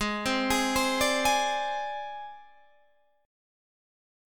G# Major Flat 5th